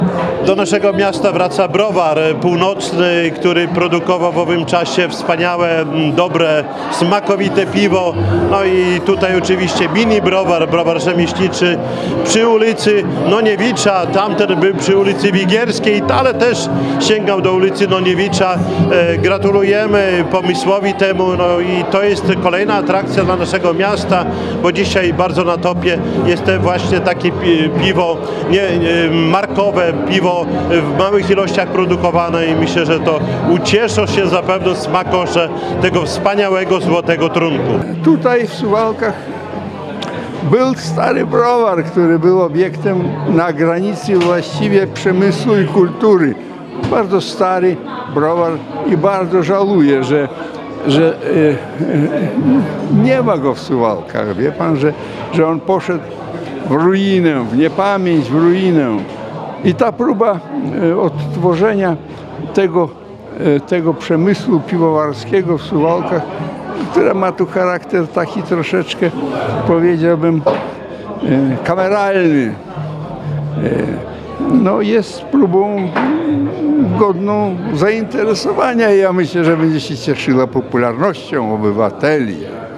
W piątek (08.12) odbyło się jego uroczyste otwarcie.W wydarzeniu wzięło udział mnóstwo gości.
Czesław-Renkiewicz-i-Andrzej-Strumiłło-o-Browarze-Północnym.mp3